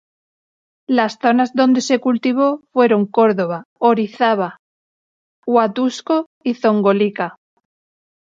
Pronounced as (IPA)
/ˈdonde/